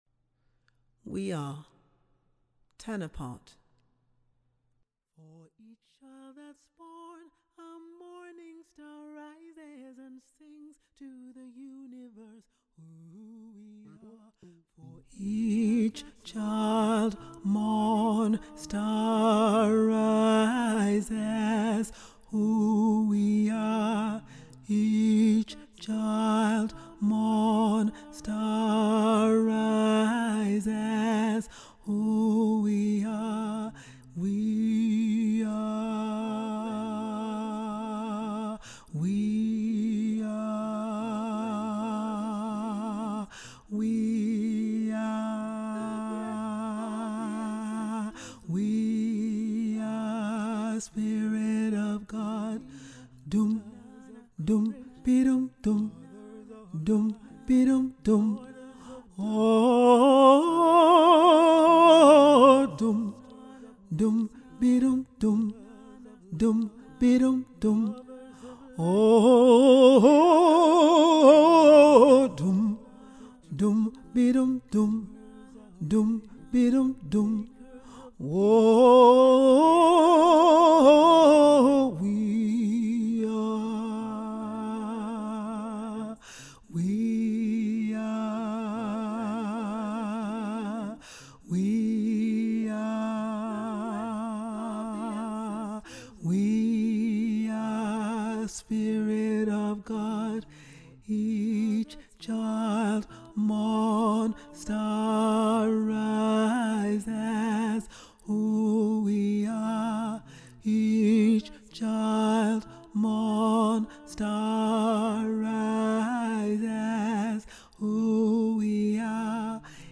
We-Are-Tenor.mp3